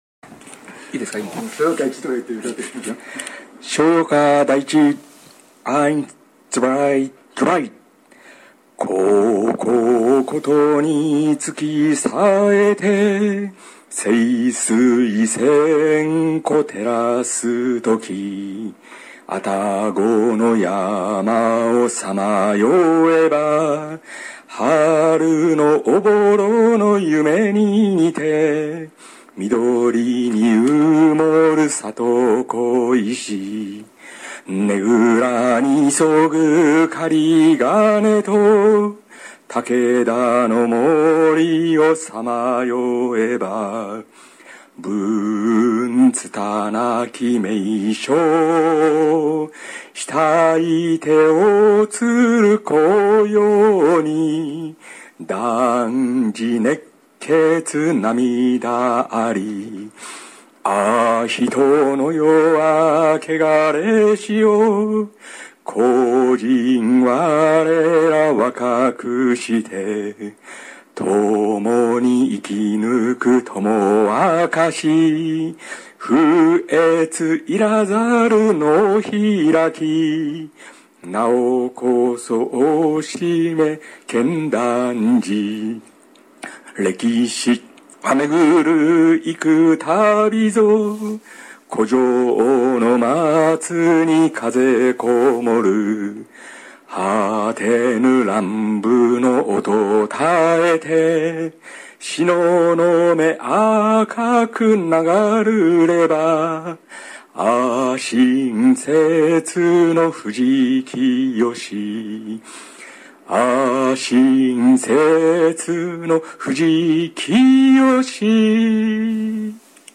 パソコンに「逍遙歌第一」をノスタルジックに歌わせてみました。